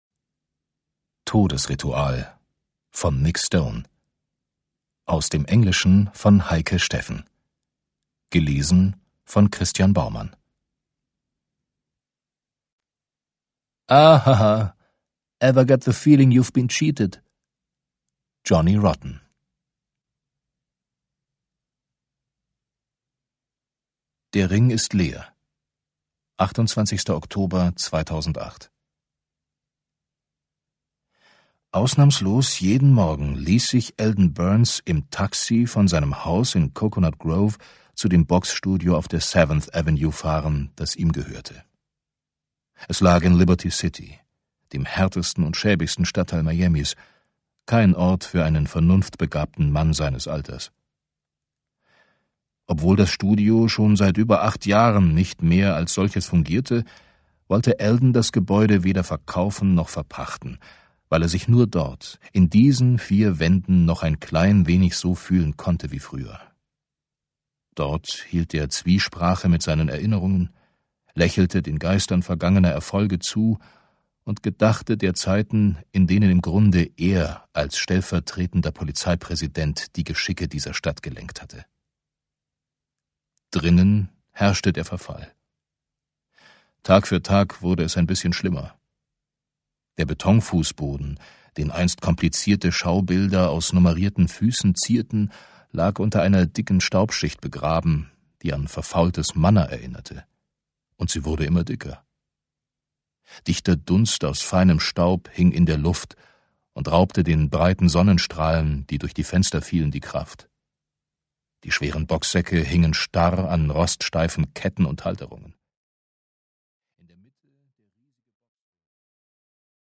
Audiobook - Todesritual